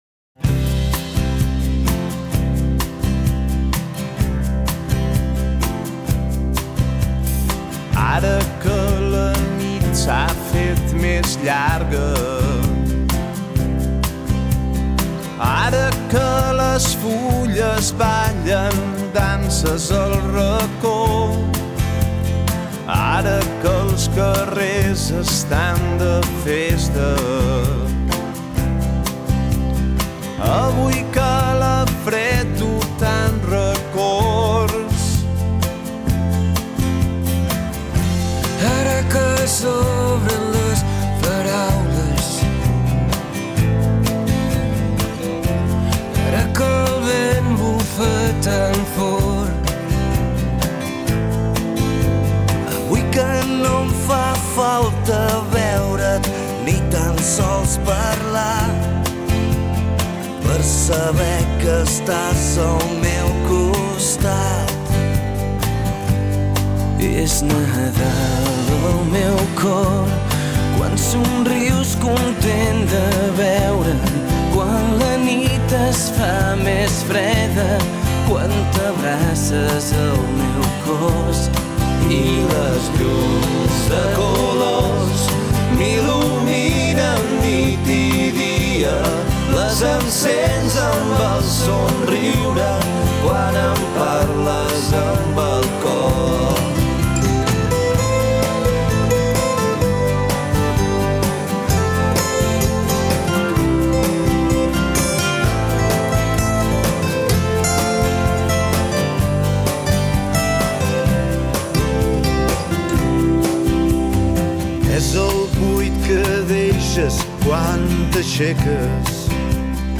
cançons de Nadal